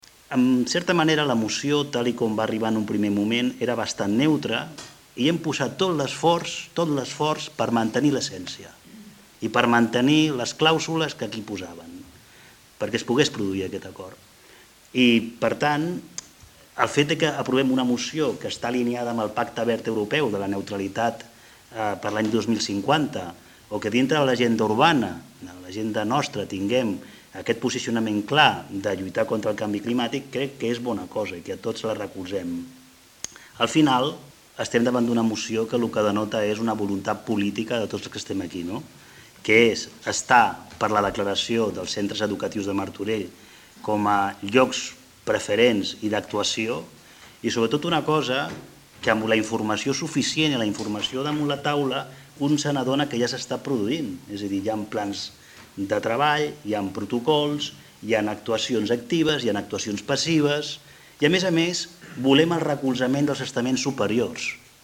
Així es va acordar, per unanimitat, en una moció presentada al Ple Municipal d’aquest dilluns.
Javier González, regidor de Transició Digital i Sostenibilitat